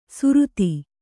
♪ suruti